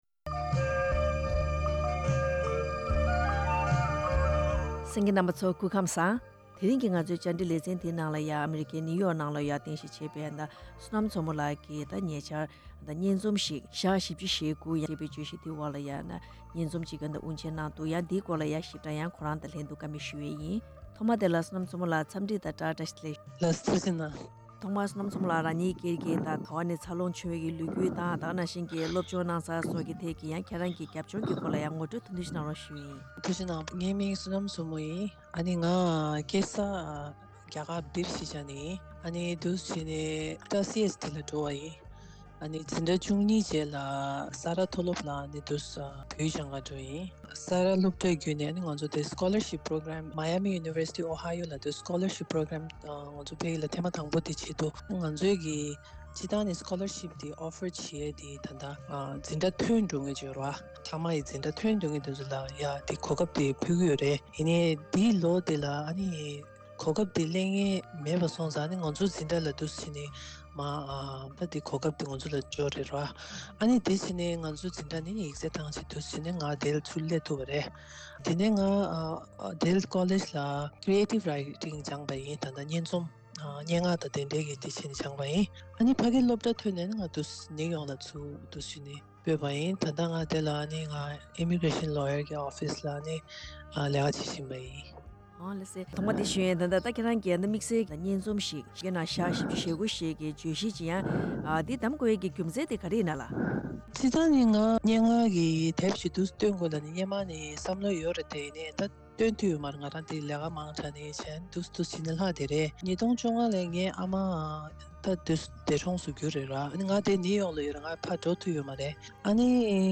གནས་འདྲི་ཕྱོགས་བསྒྲིགས་ཞུས་པ་གསན་རོགས་གནང་།